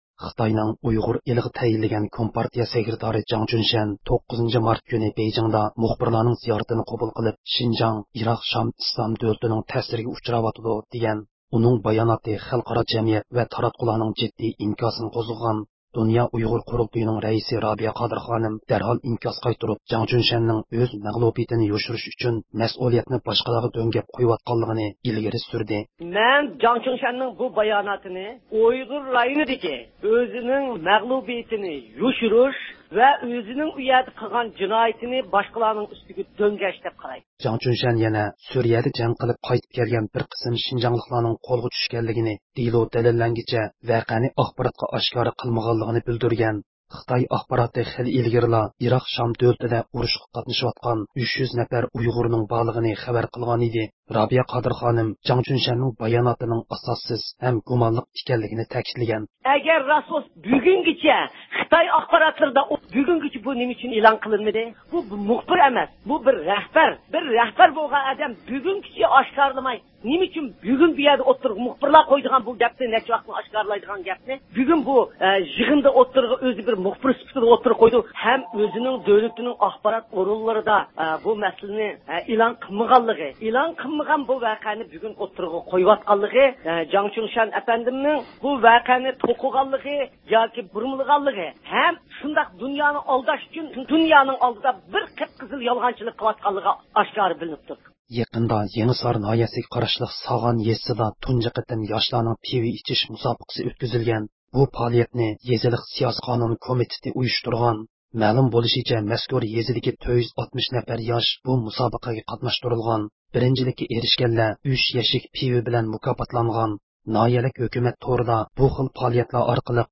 erkin-asiya-radiosi.jpgئەركىن ئاسىيا رادىئوسى ئۇيغۇر بۆلۈمى ھەپتىلىك خەۋەرلىرى